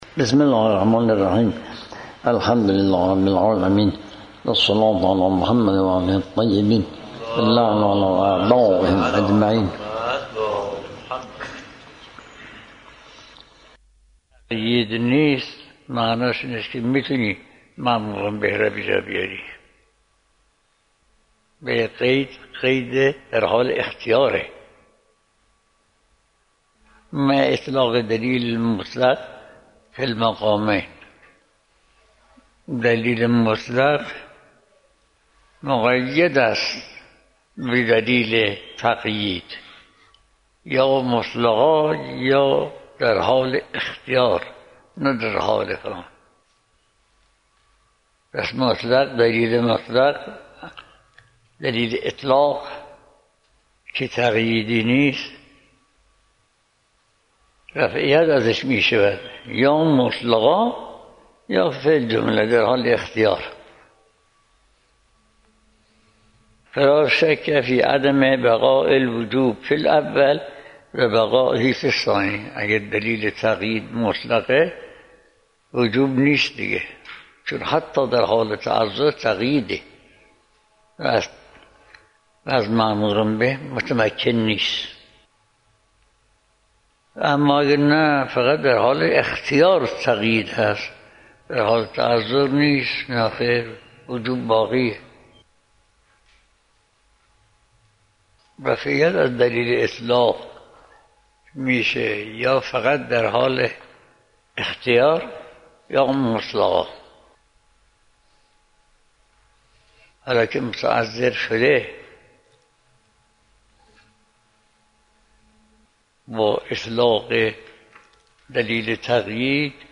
آيت الله بهجت - خارج اصول | مرجع دانلود دروس صوتی حوزه علمیه دفتر تبلیغات اسلامی قم- بیان